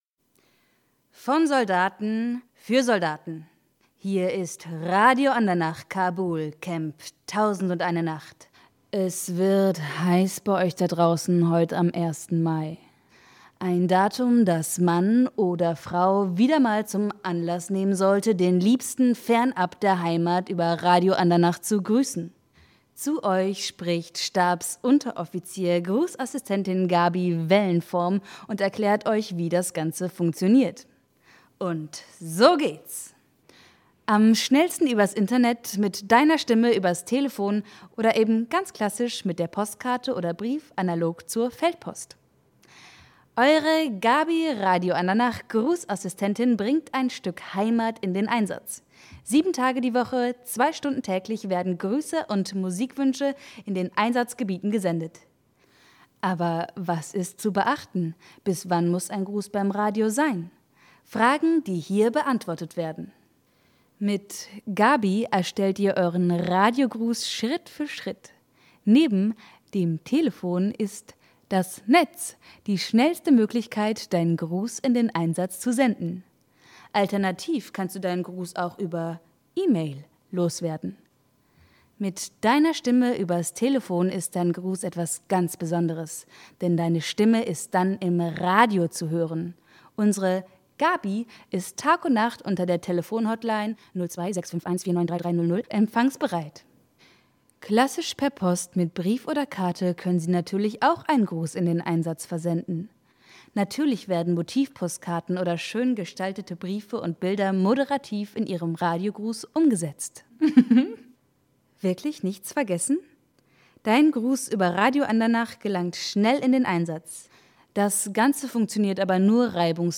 im Theater im Schokohof Berlin
Hörbeispiel aus der Inszenierung